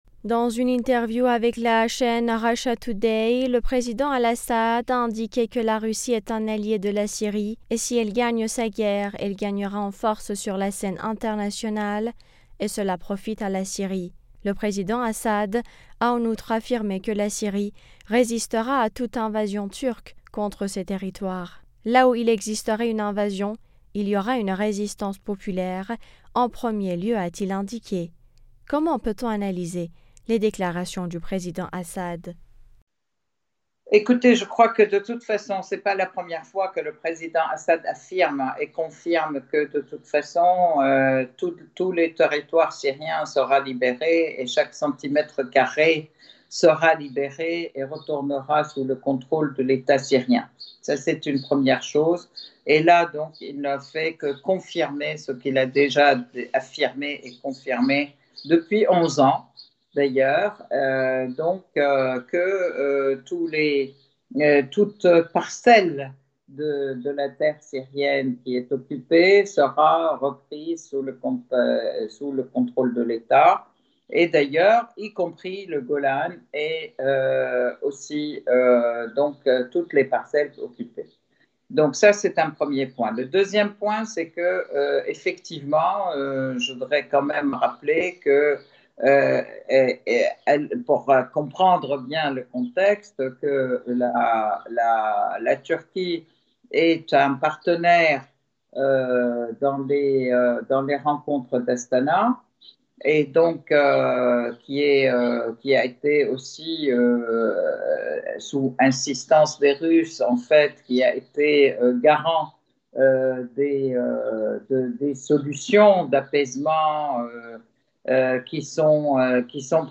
analyste franco-syrienne des questions internationales nous en dit plus.